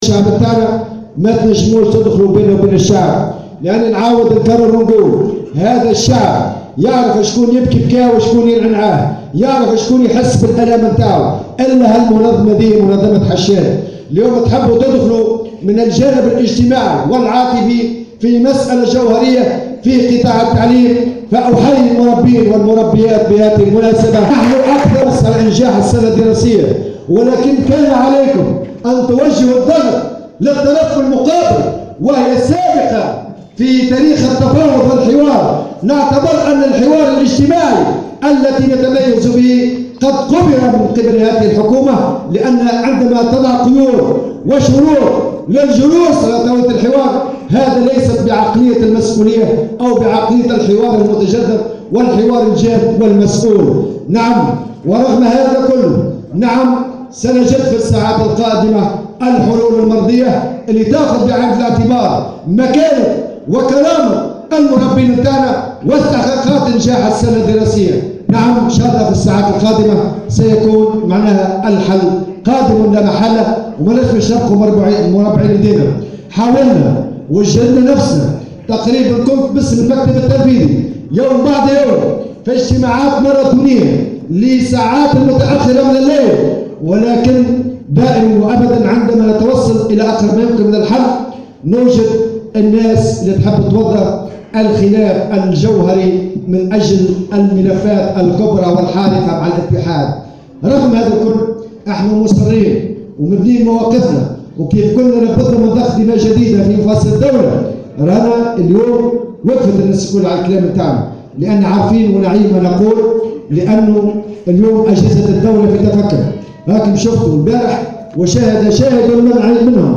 أكد الأمين العام للإتحاد العام التونسي للشغل نور الدين الطبوبي في تصريح لمراسلة الجوهرة "اف ام" خلال الكلمة التي ألقاها خلال المؤتمر العادي 23 للإتحاد الجهوي للشغل بنابل أنه سيتم التوصل في الساعات القادمة للحلول المناسبة لأزمة التعليم .